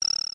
The "Dragon Coin" sound effect from Super Mario World.